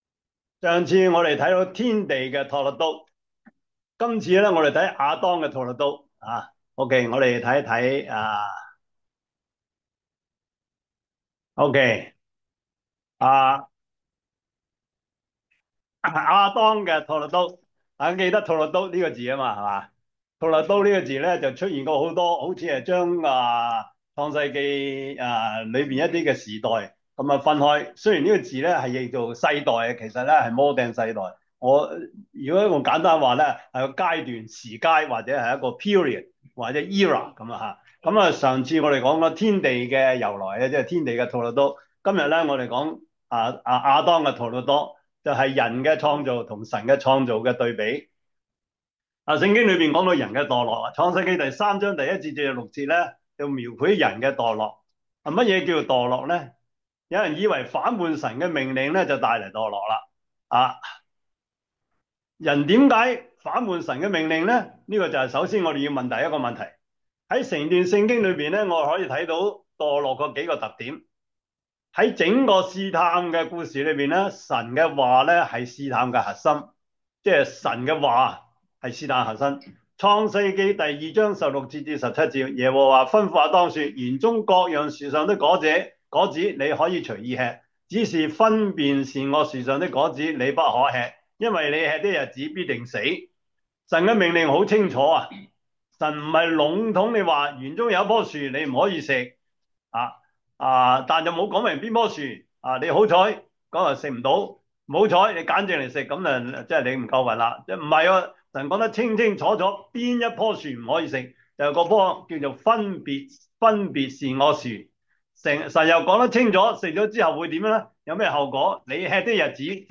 中文主日學